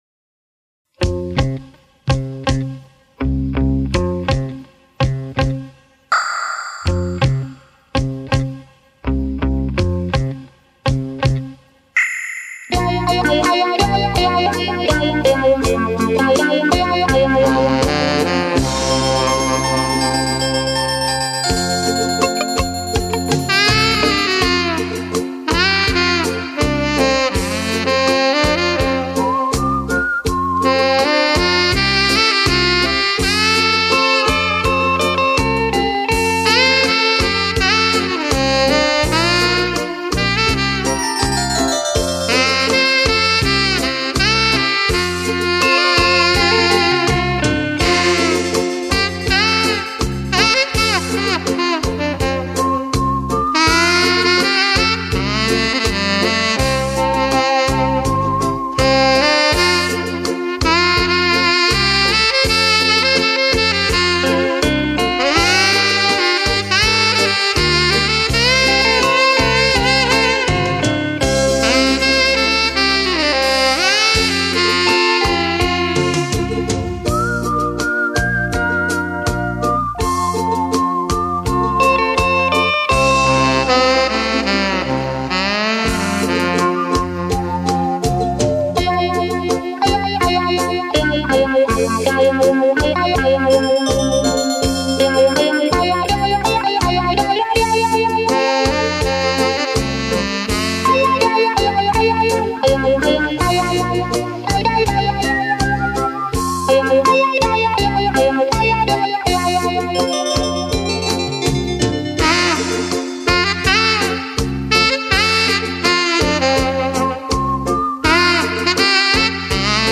萨克斯专辑